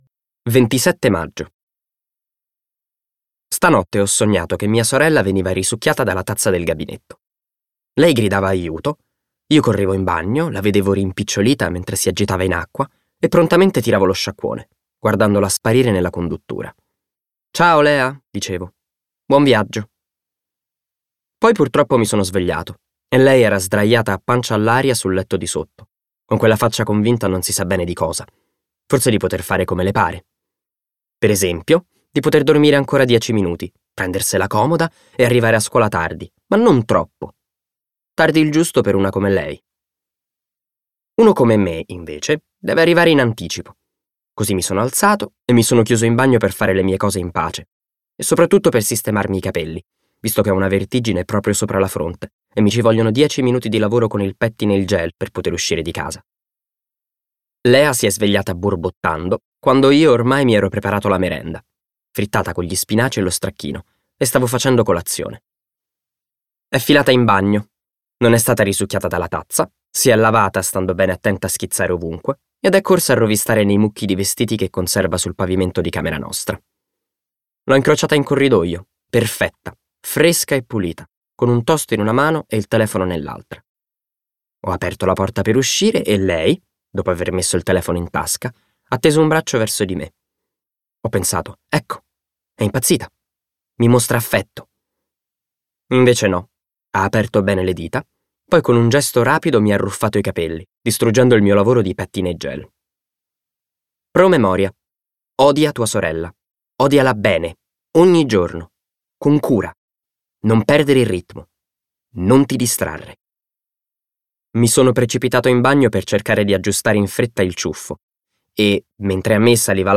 Versione audiolibro integrale